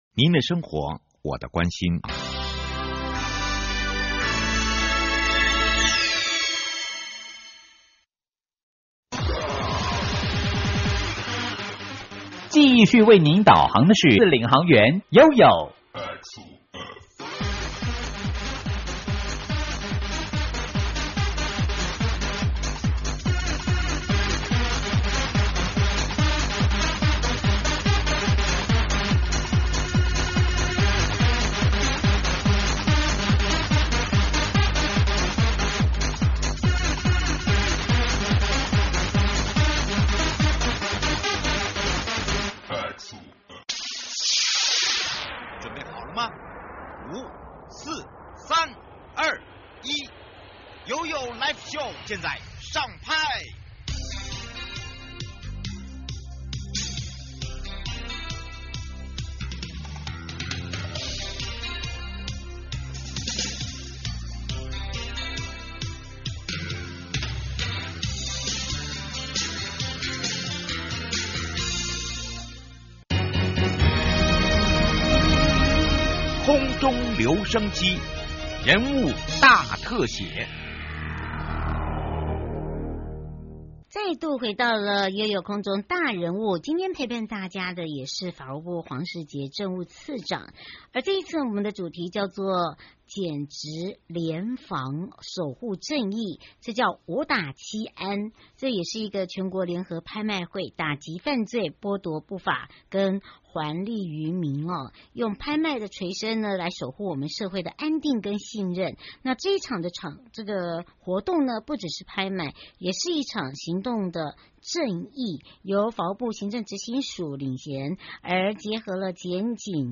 受訪者： 1.花東縱谷管理處許宗民處長 2.西拉雅管理處許主龍處長